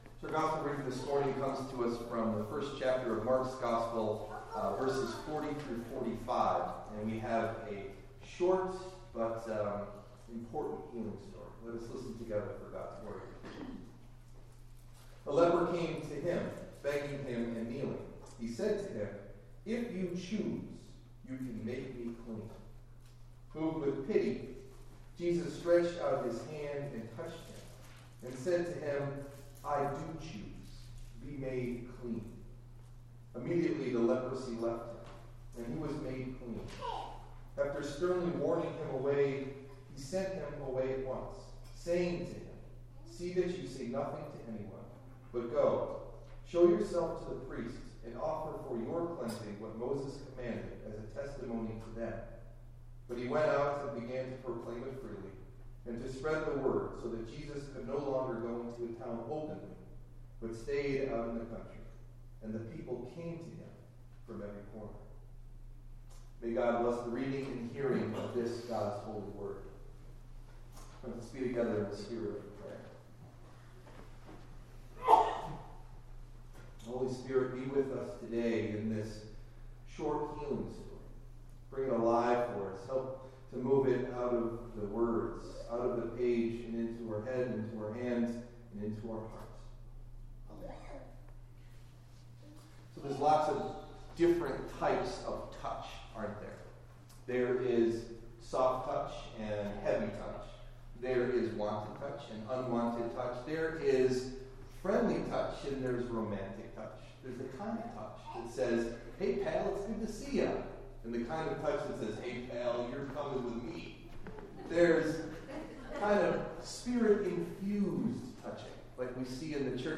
Sermon Delivered at: The United Church of Underhill (UCC and UMC)